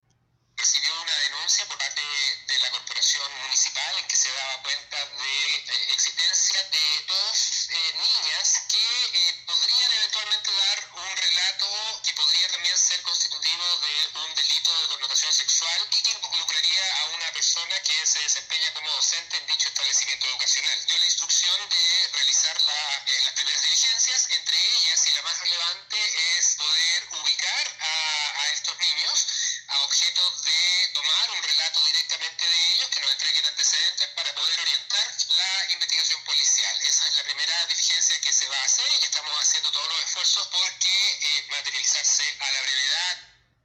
Asimismo el Fiscal Enrique Canales, confirmó la existencia de una denuncia por parte de la Corporación Municipal para iniciar una investigación respecto a los hechos.